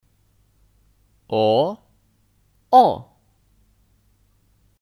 哦 (ó 哦)